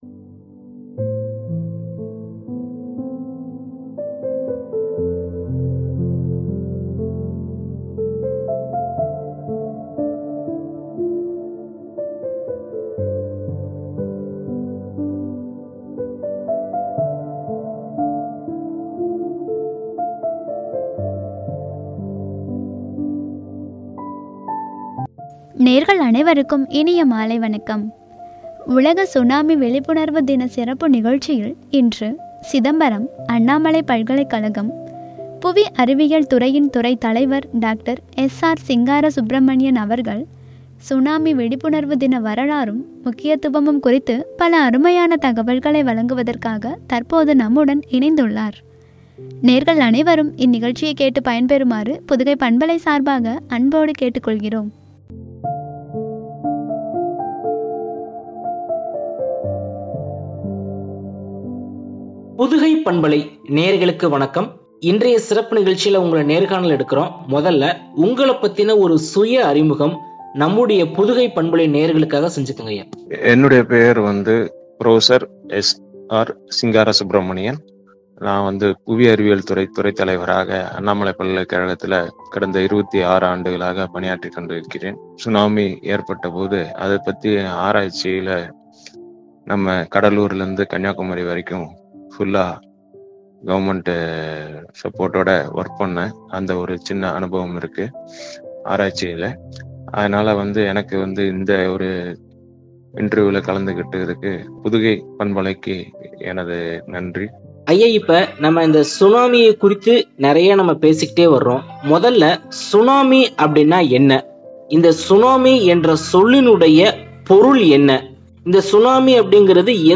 முக்கியத்துவமும்” என்ற தலைப்பில் வழங்கிய உரையாடல்.